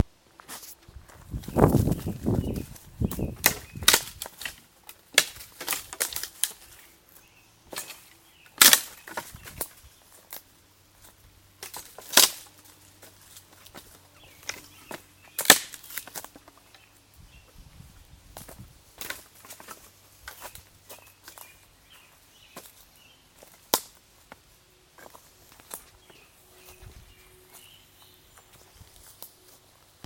描述：鸟儿歌唱
Tag: 鸟鸣声 气氛 户外 线索 自然 现场录音